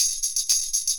Session 14 - Tambourine.wav